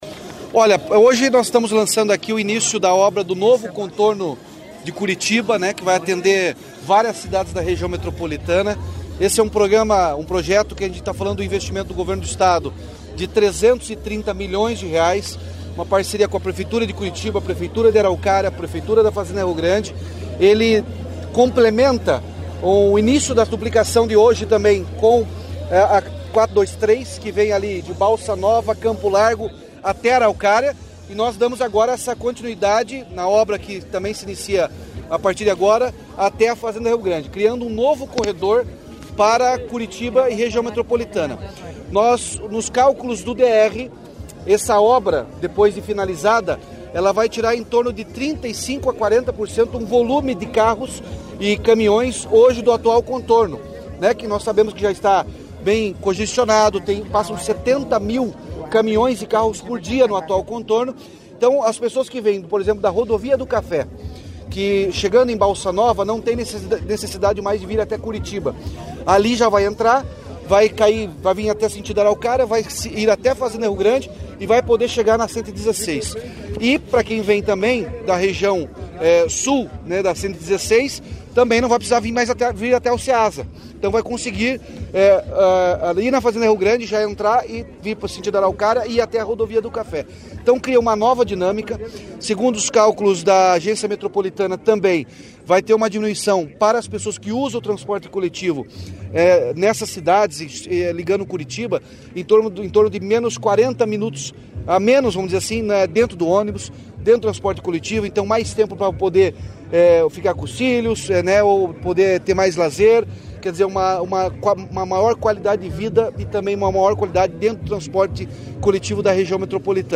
Sonora do governador Ratinho Junior sobre a autorização das obras do novo Contorno Sul de Curitiba